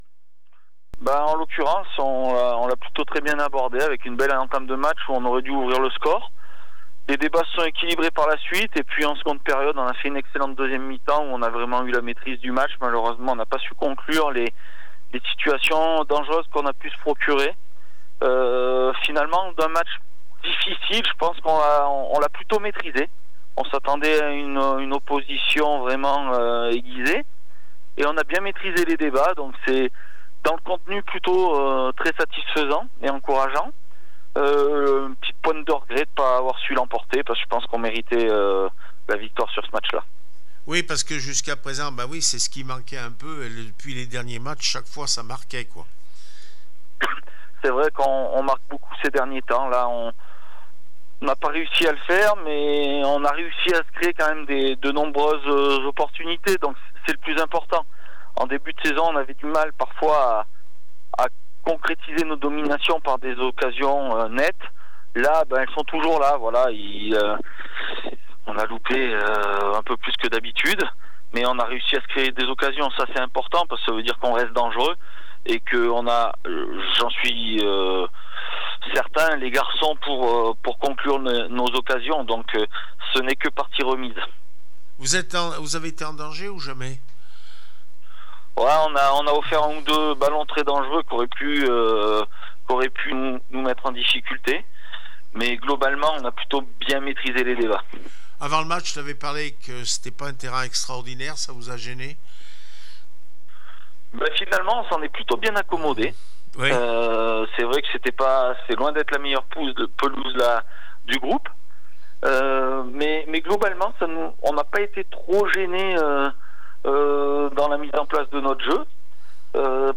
28 novembre 2024   1 - Sport, 1 - Vos interviews
n2 foot Hyères 0-0 le puy foot 43 réaction après match